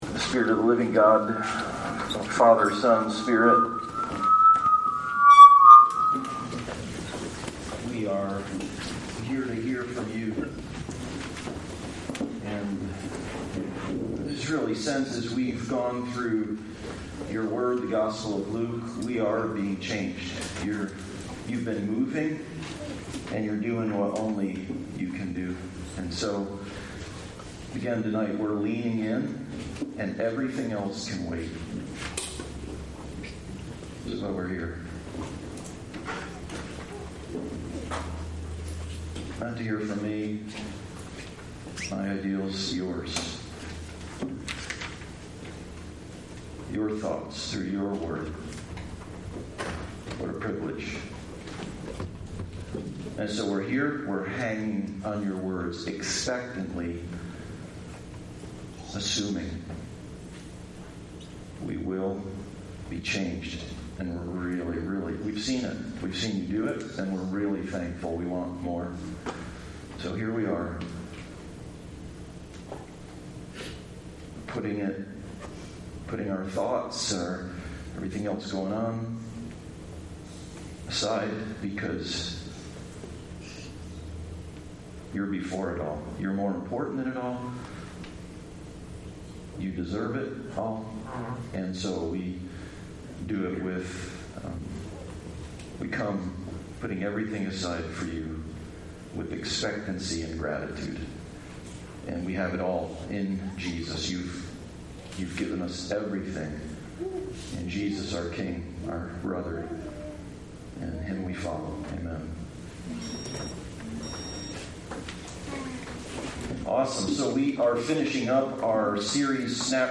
Snapshots of Jesus Passage: Luke 24:44-49; 1 Corinthians 15:22-58 Service Type: Sunday Service